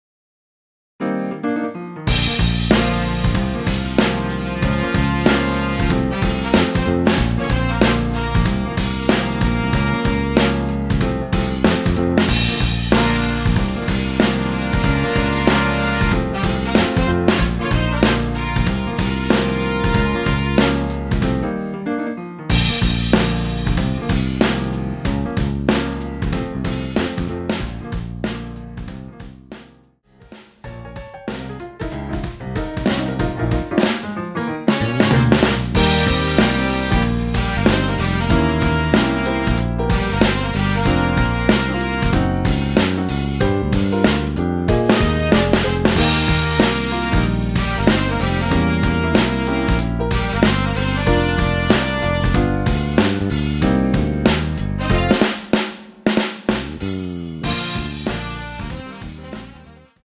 Bb
◈ 곡명 옆 (-1)은 반음 내림, (+1)은 반음 올림 입니다.
앞부분30초, 뒷부분30초씩 편집해서 올려 드리고 있습니다.
중간에 음이 끈어지고 다시 나오는 이유는